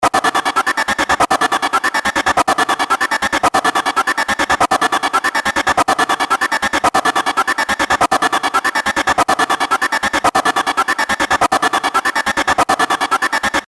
Slicing.wav